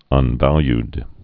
(ŭn-vălyd)